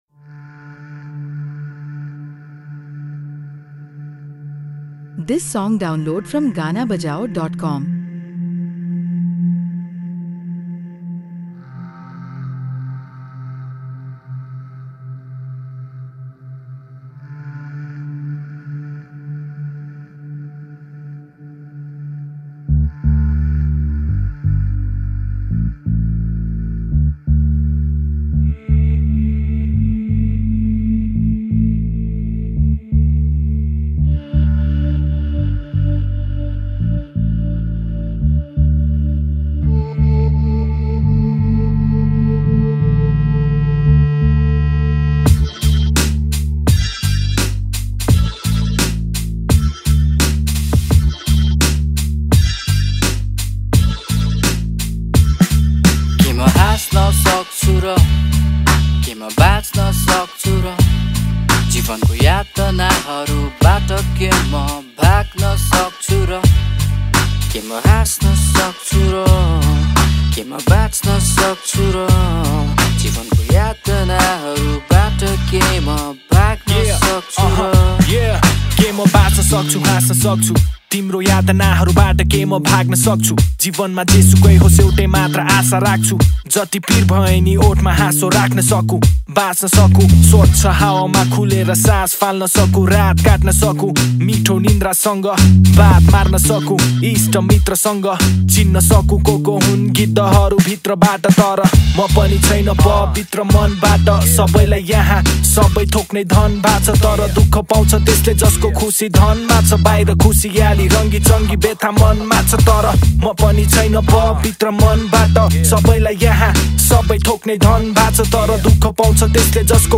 # Nepali Hiphop Song